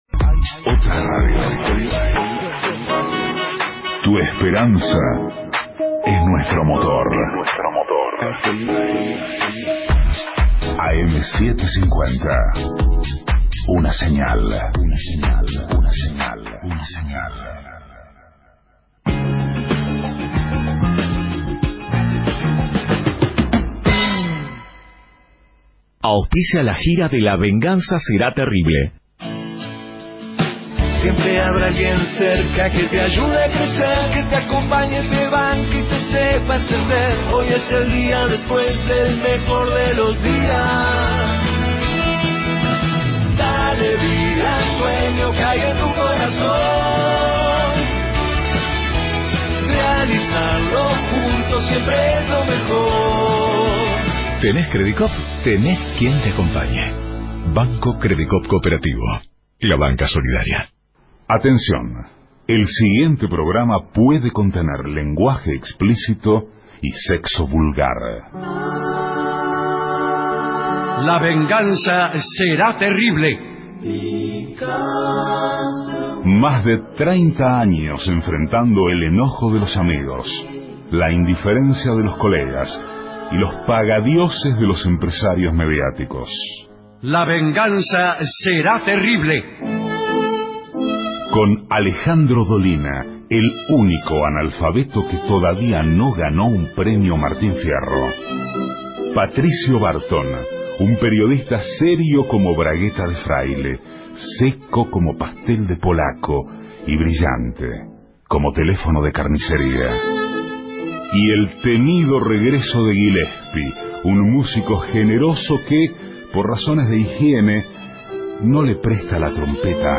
Centro Cultural Caras & Caretas, Buenos Aires